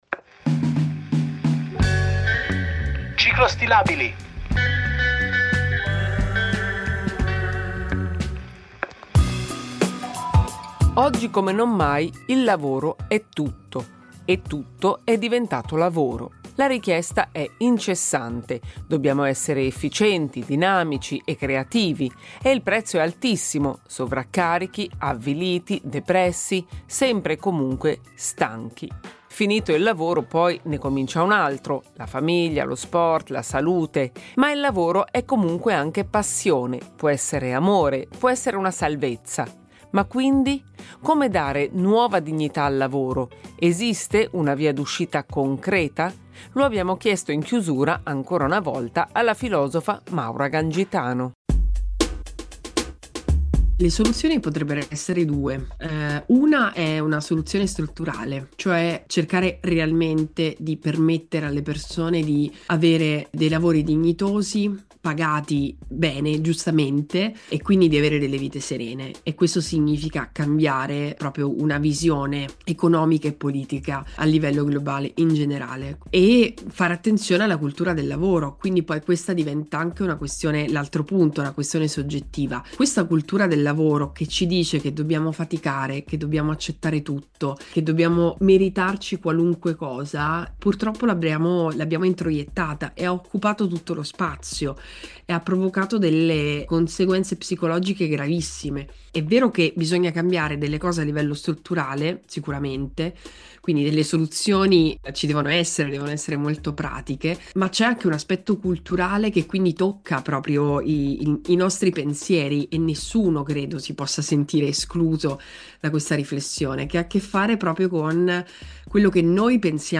Un dialogo con Maura Gancitano